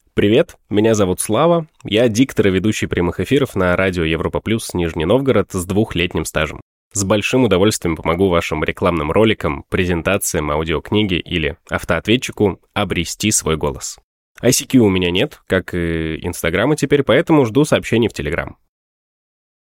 AKG P120, Behringer U22, Reaper